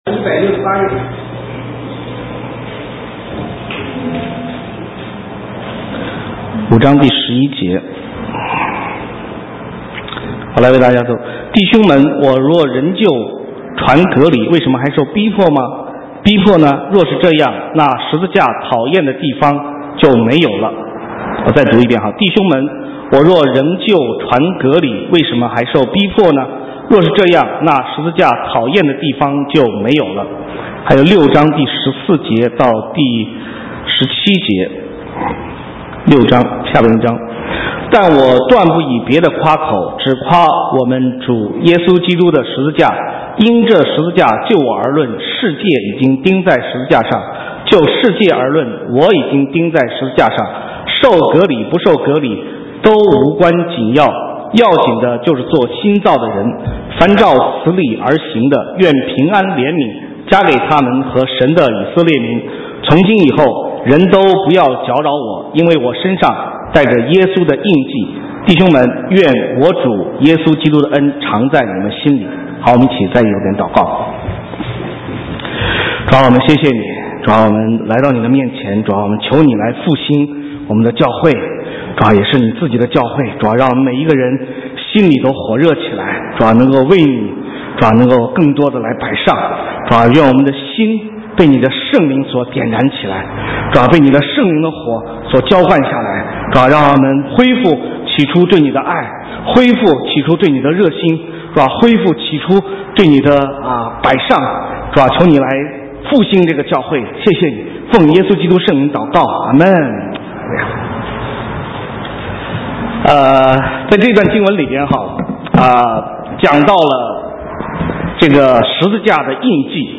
神州宣教--讲道录音 浏览：十字架的印记 (2011-08-14)